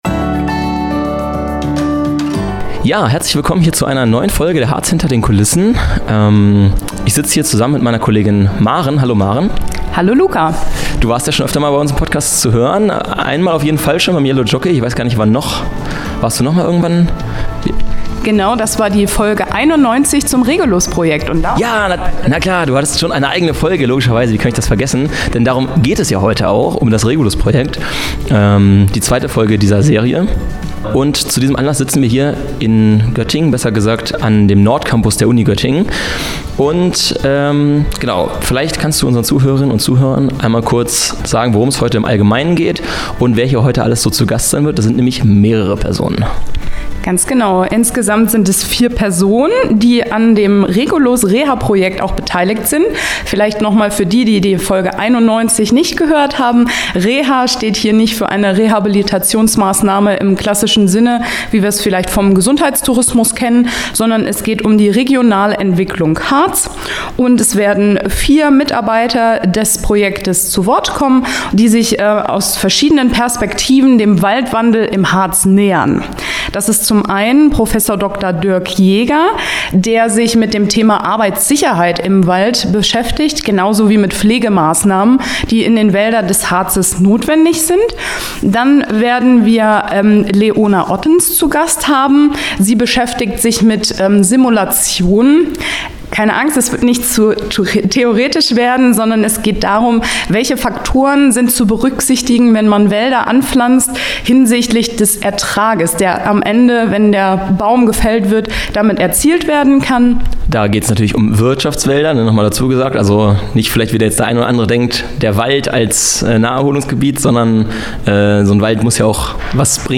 In dieser Woche geht es noch einmal um das Regulus Reha Projekt. In dieser zweiten Folge kommen vier Mitarbeiter/innen des Projektes zu Wort